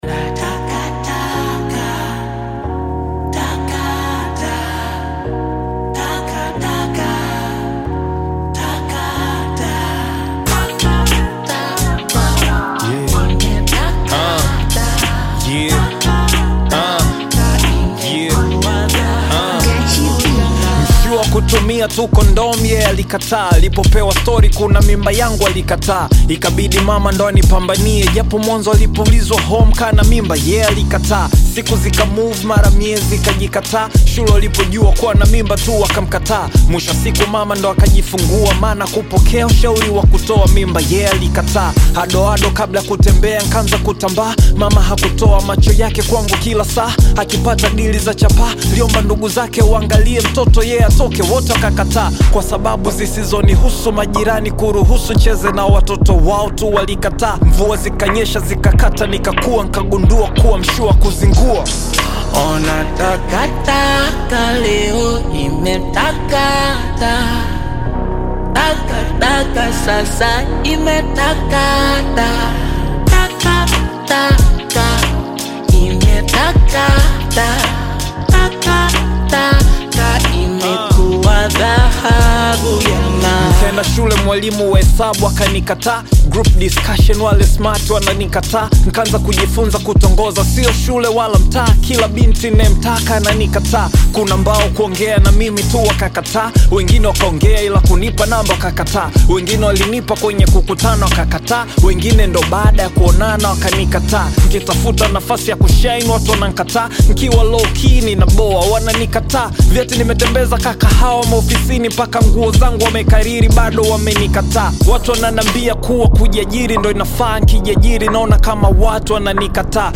Built on soulful Bongo Flava rhythms and stirring melodies